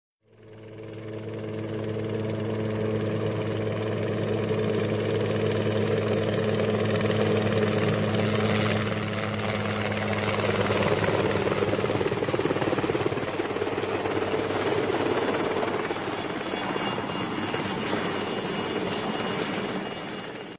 helicopter.mp3